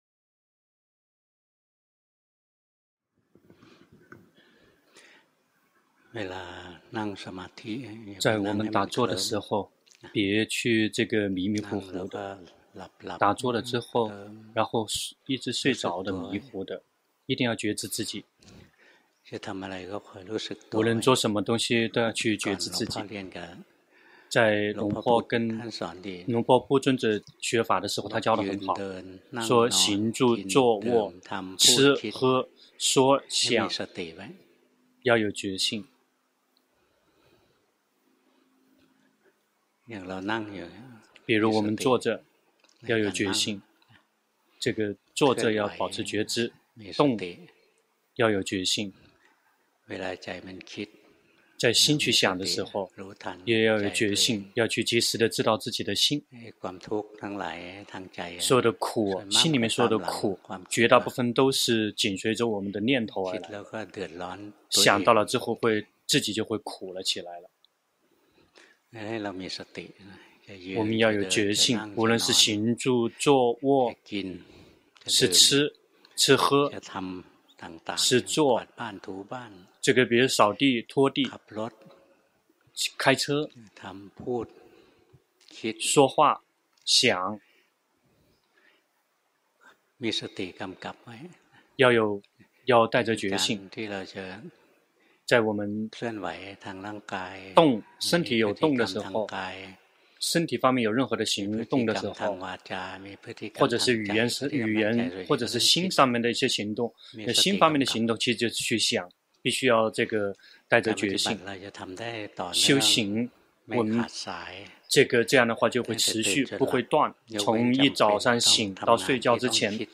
同聲翻譯